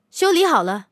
LT-35修理完成提醒语音.OGG